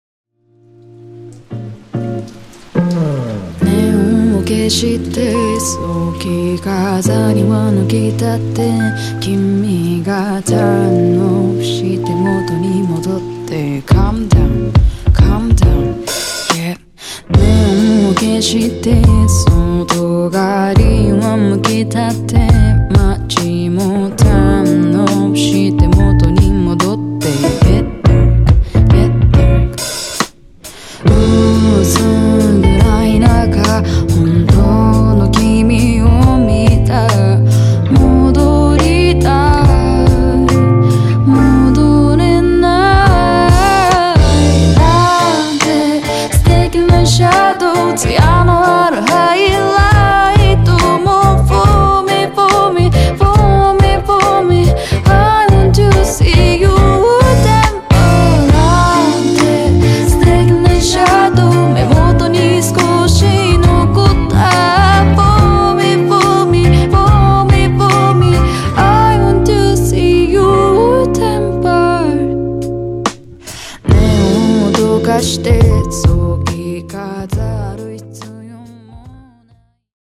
メンバー全員2002年生まれの4人組バンド。